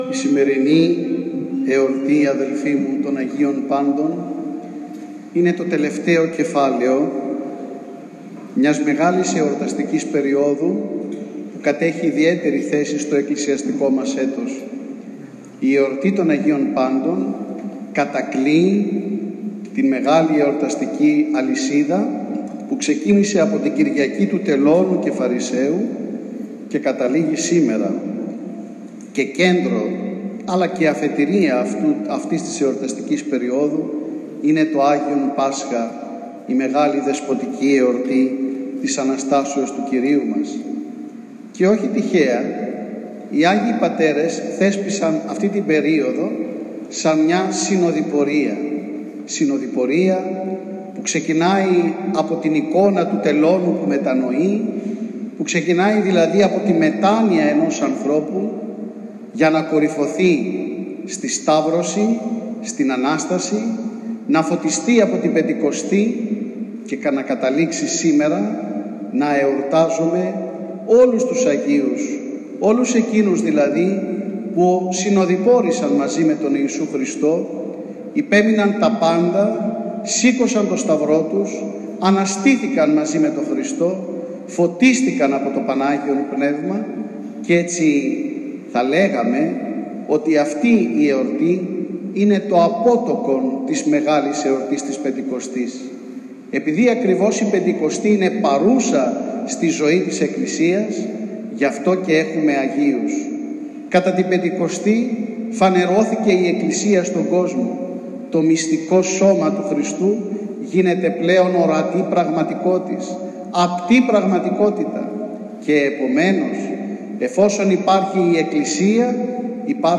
«Η εορτή των Αγίων Πάντων είναι το απότοκον της μεγάλης εορτής της Πεντηκοστής, ενώ με την έλευση του Αγίου Πνεύματος η εκκλησία γίνεται εργαστήρι Αγίων, δηλαδή οδοδεικτών για όλους μας», λέει στο κήρυγμά του στον Ιερό Ναό Αγίου Χαραλάμπους Πολυγώνου επί τη εορτή των Αγίων Πάντων ο Θεοφιλέστατος Επίσκοπος Ευρίπου κ. Χρυσόστομος.